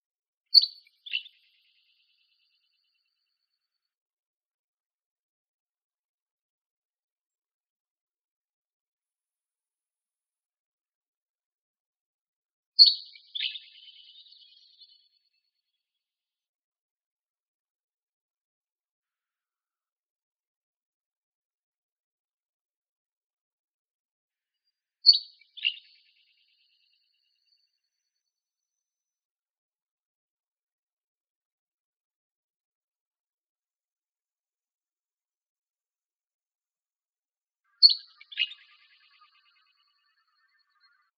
duskyflycatcher.wav